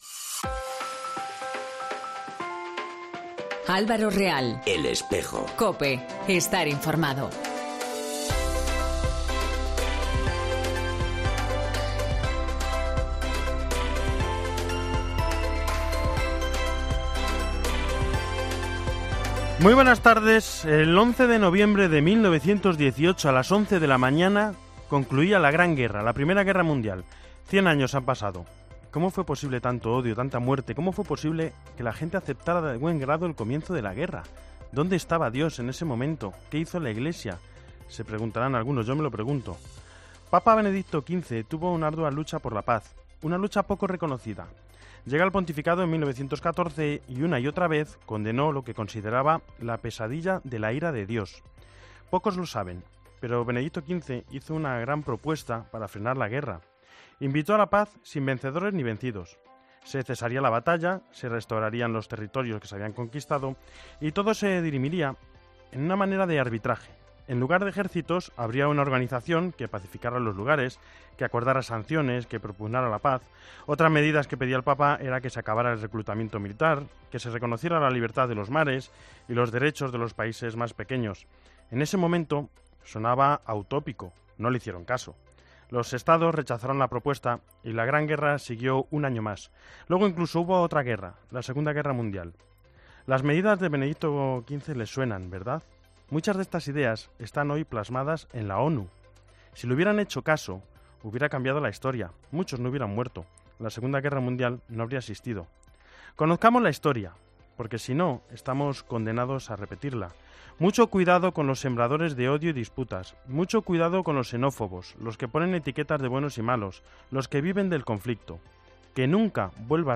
entrevista sobre Diócesis de Tarazona y vida de Hispanoamérica.